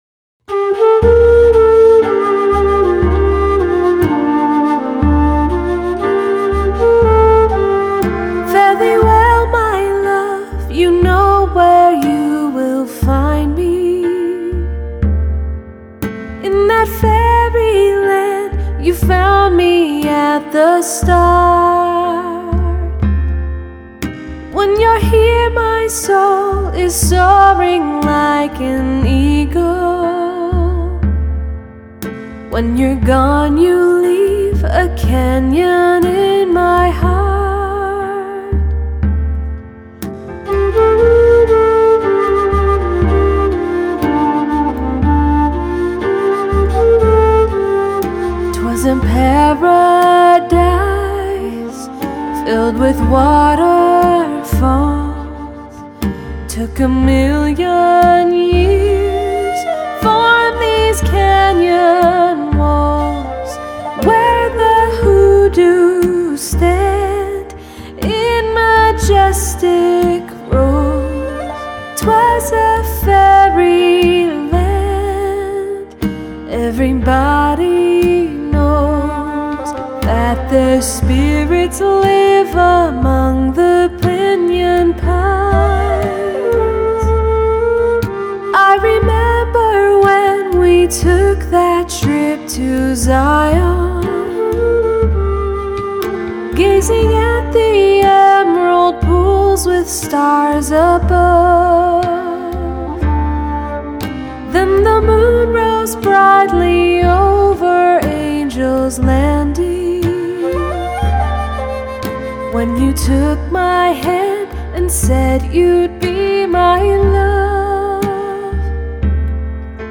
The song is both spiritual and uplifting.